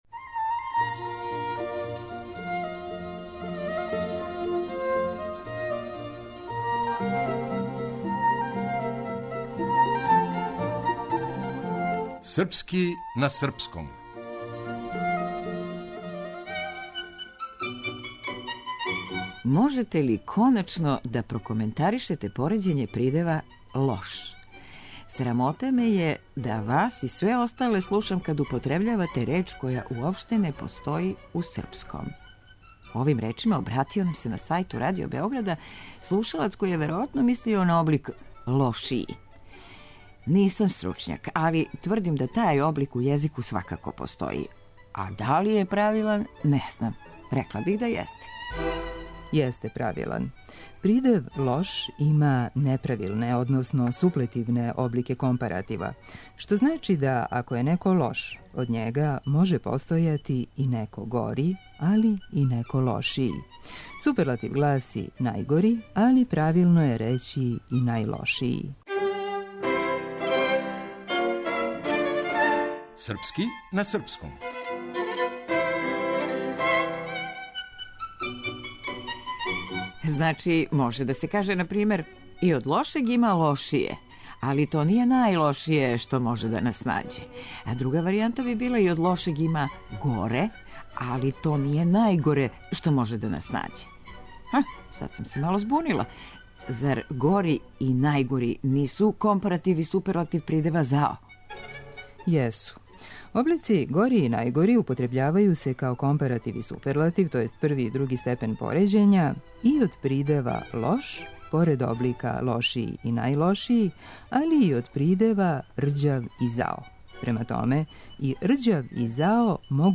Драмска уметница